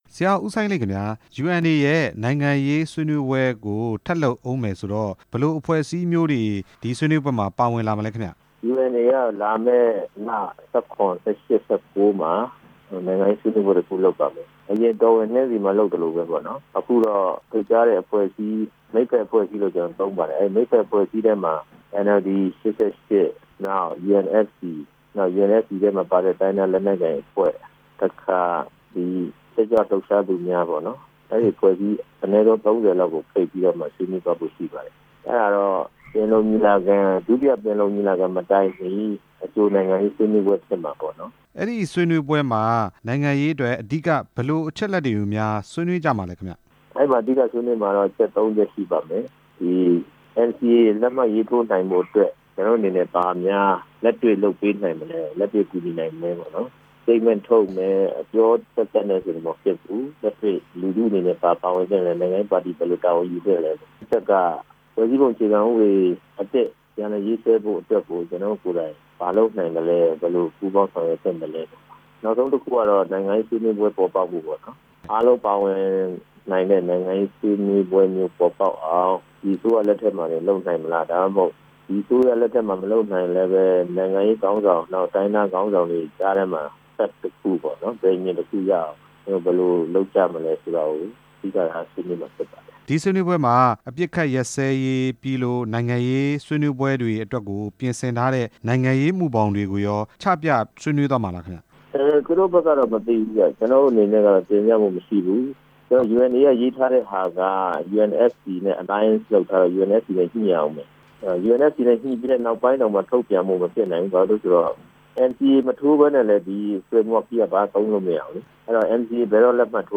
ဖေဖော်ဝါရီလအတွင်းမှာ ကျင်းပမဲ့ နိုင်ငံရေးအကြိုဆွေးနွေးပွဲအကြောင်း မေးမြန်းချက်